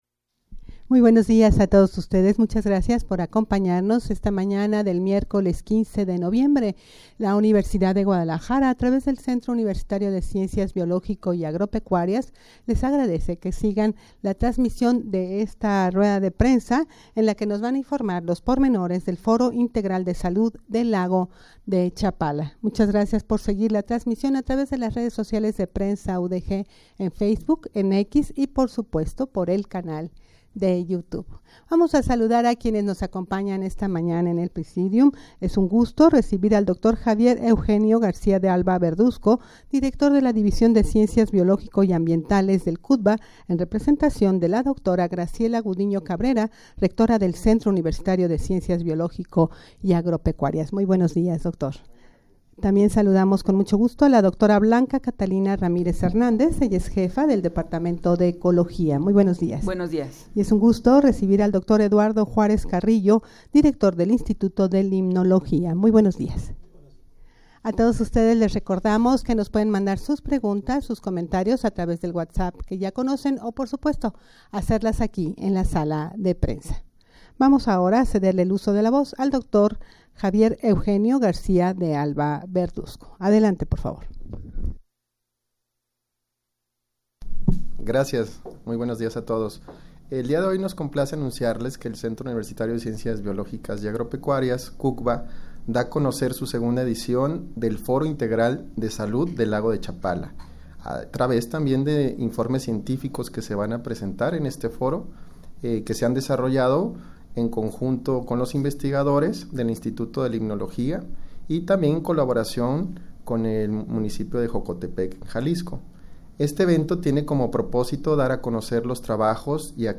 Audio de la Rueda de Prensa
rueda-de-prensa-para-informar-los-pormenores-del-foro-integral-de-salud-del-lago-de-chapala.mp3